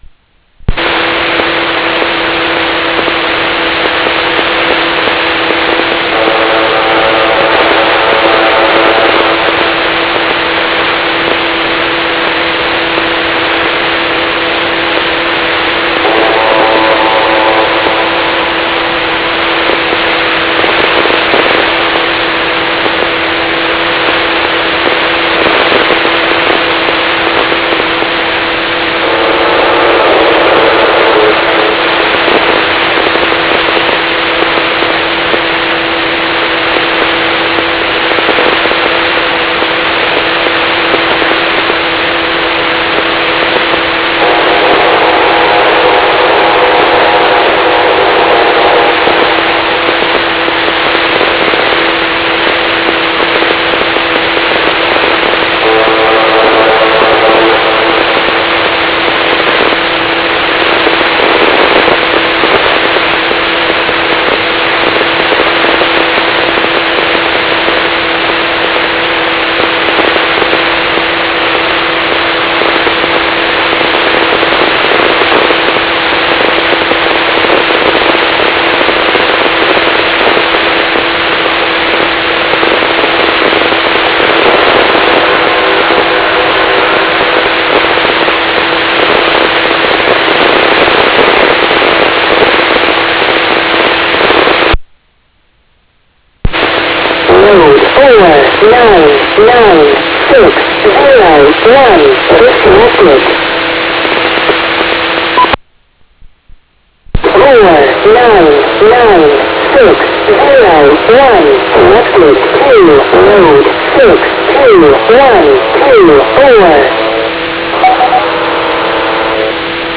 Amateur radio tranmission sounds like musical instruments with static, morse code, node connected and disconnected
Received on my amateur radio connected to a computer in my garage in Arkansas, approximately 2024-Nov-04. This audio was not altered in any way, other than a few cuts.
2:05 Morse code with echo
This radio transmission includes the eerie sound of a musical instrument, like a trumpet sounding a low note, playing a single tone to announce a warning.